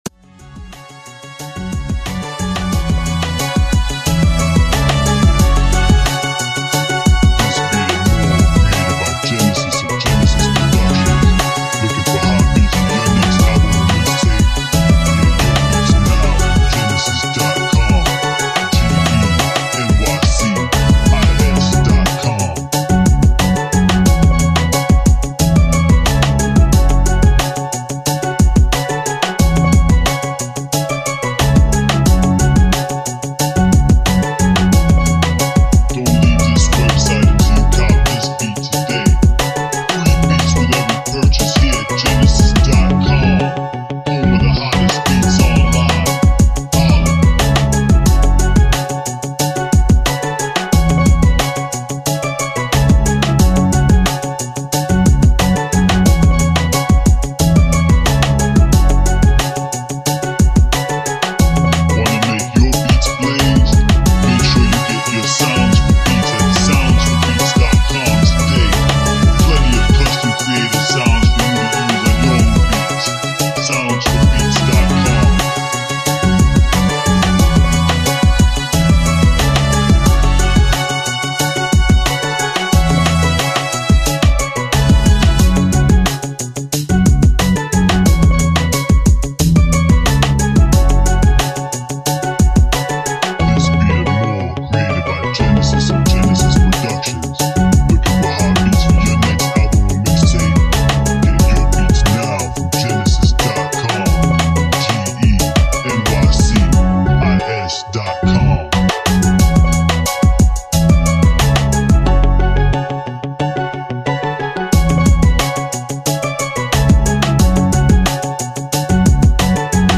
Storytelling Beats